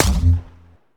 hit2.wav